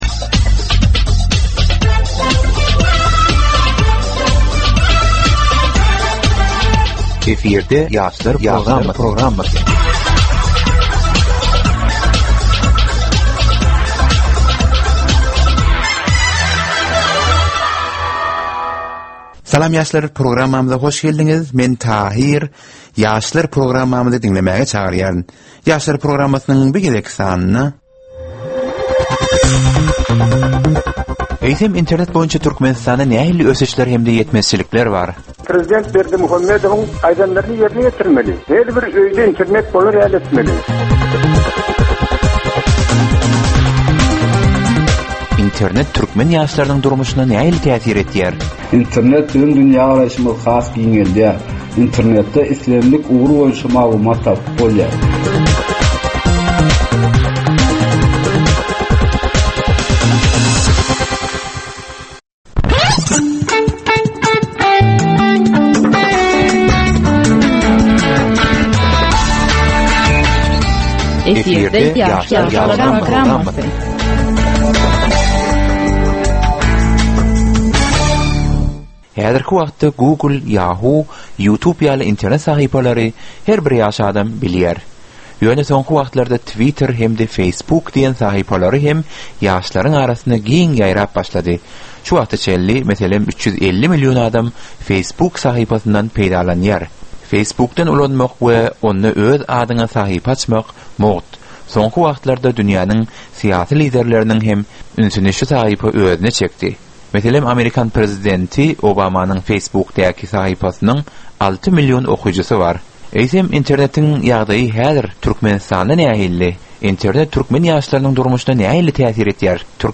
Türkmen we halkara yaşlarynyň durmuşyna degişli derwaýys meselelere we täzeliklere bagyşlanylyp taýýarlanylýan 15 minutlyk ýörite gepleşik. Bu gepleşikde ýaşlaryn durmuşyna degişli dürli täzelikler we derwaýys meseleler barada maglumatlar, synlar, bu meseleler boýunça adaty ýaşlaryň, synçylaryň we bilermenleriň pikrileri, teklipleri we diskussiýalary berilýär. Gepleşigiň dowamynda aýdym-sazlar hem eşitdirilýär.